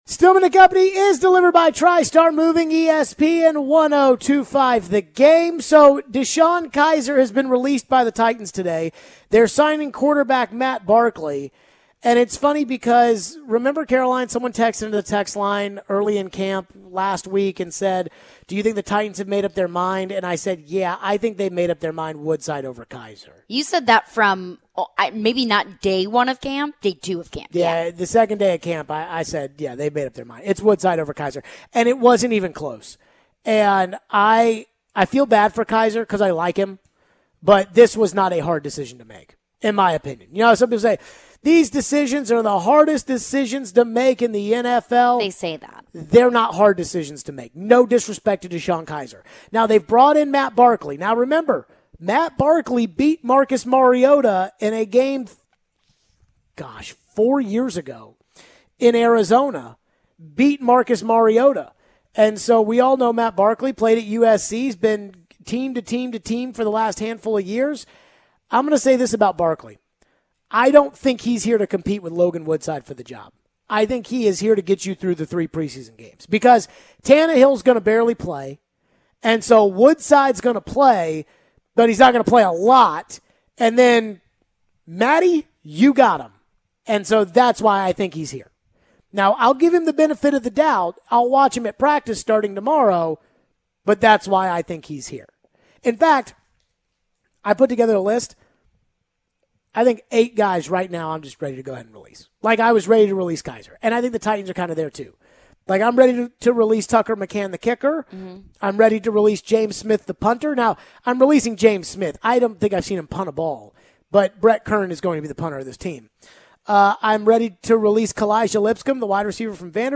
We take your phones on the Colts. Plus some thoughts on the Preds offseason thus far.